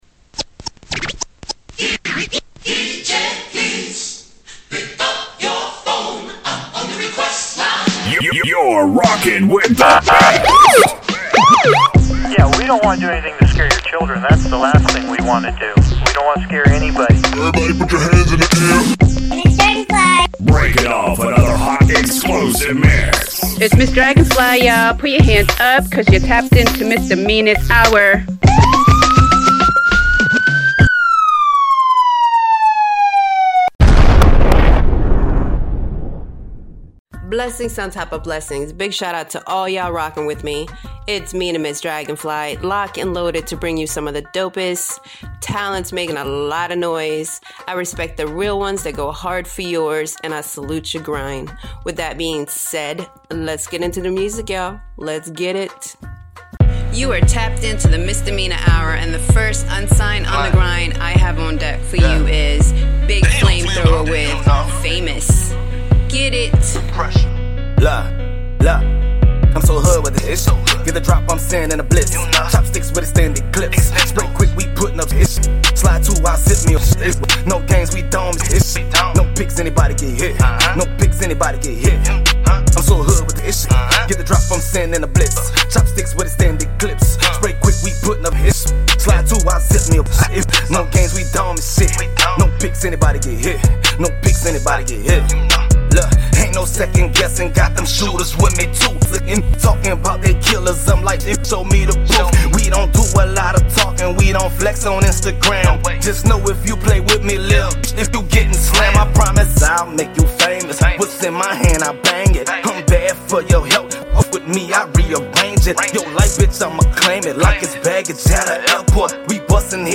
Vibe Out 2022 Interview with Karyn White